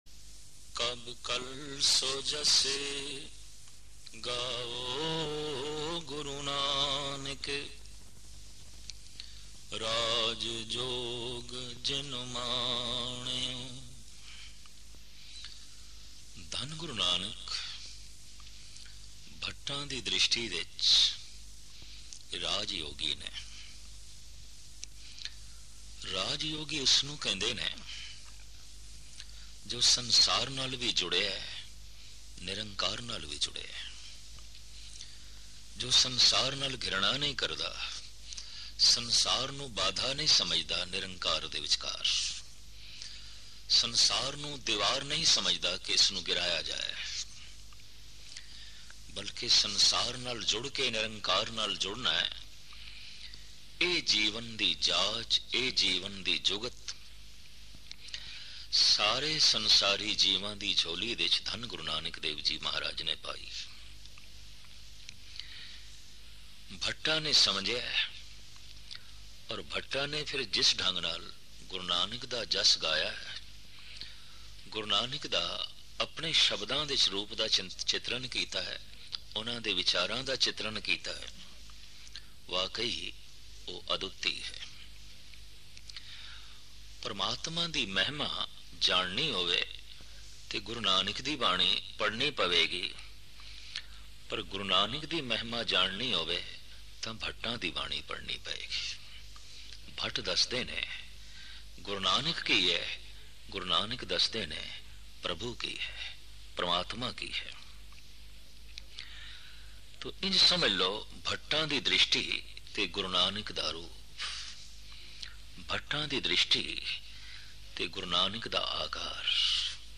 Katha
Album: JagatBaba Genre: Gurmat Vichar